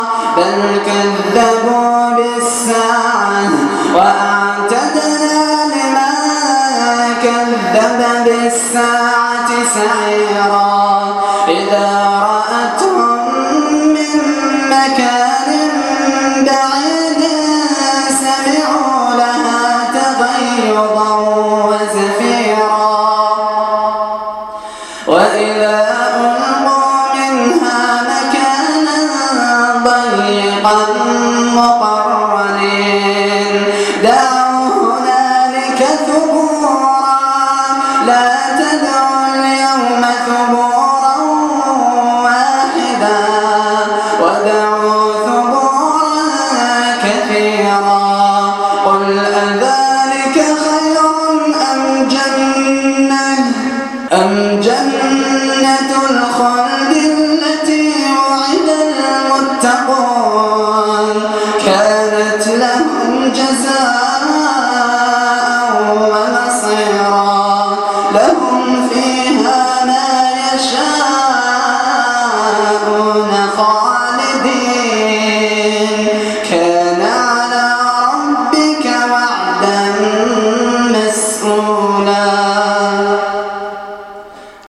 تلاوة خاشعة ومؤثرة